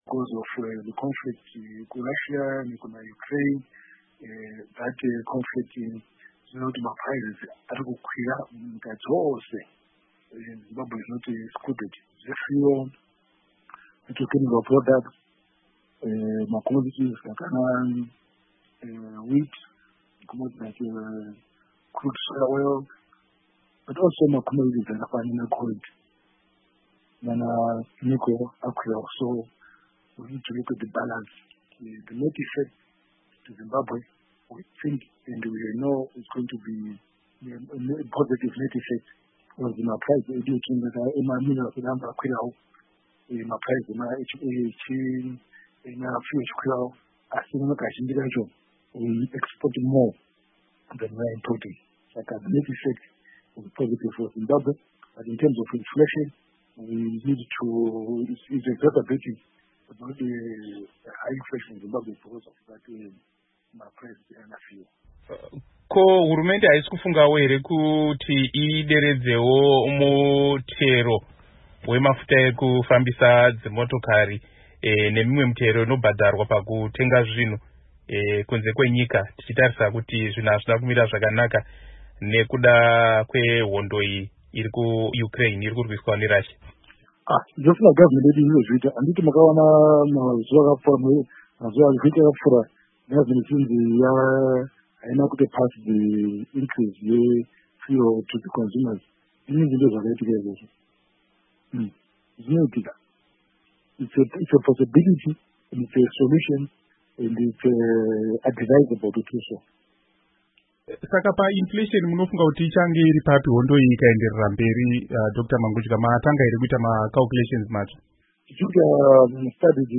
Hurukuro naDoctor John Mangudya